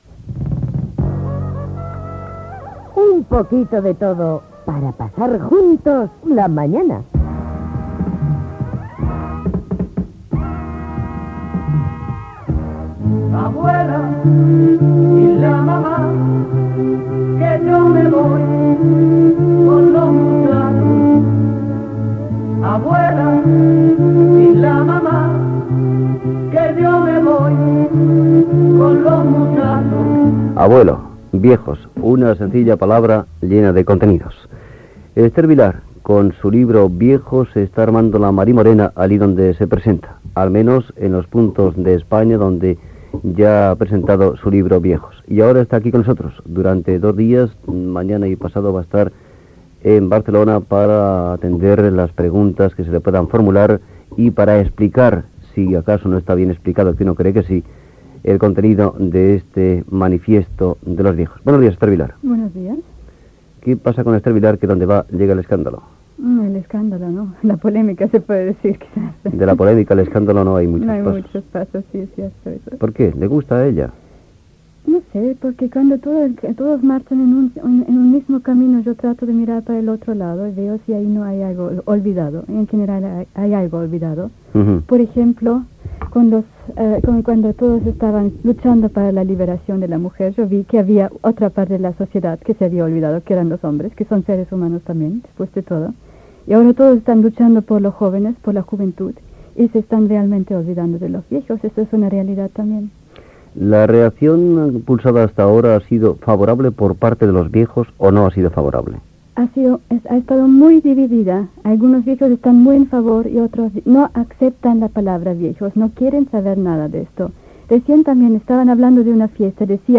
Indicatiu del programa, entrevista a l'escriptora i sociòloga Esther Vilar pel seu llibre "Manifiesto de los nuevos viejos"
Entreteniment